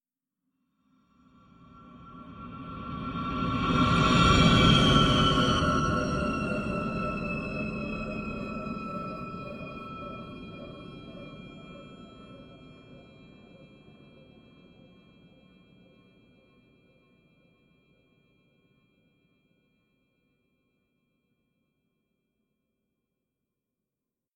Здесь собраны необычные звуковые композиции: от тонких природных мотивов до абстрактных эффектов, способных вызвать яркие ассоциации.
Звук внезапного прозрения человека